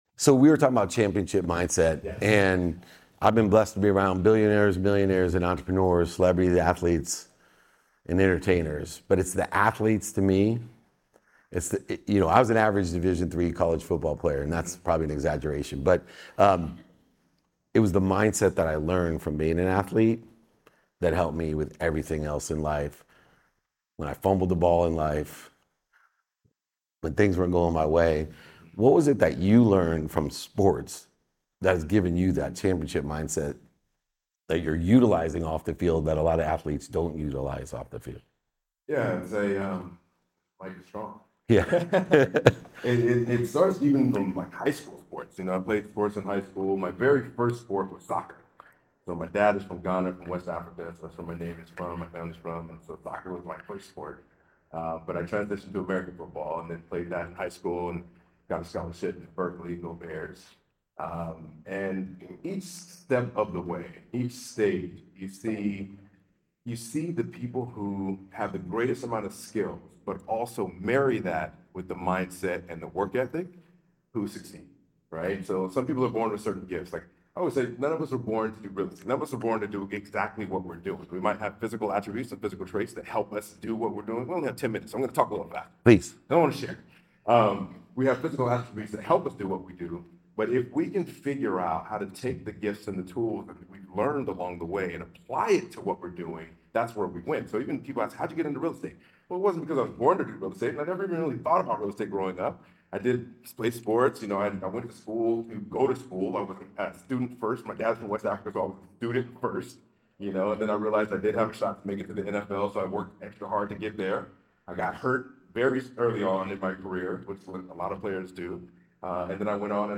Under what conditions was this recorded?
Today’s episode was recorded at the LFG Society in San Francisco